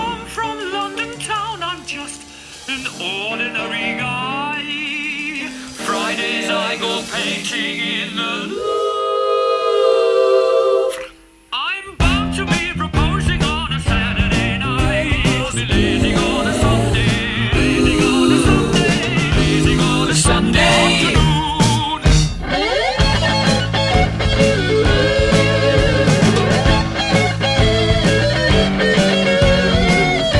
Enregistrement remasterisé
Rock et variétés internationales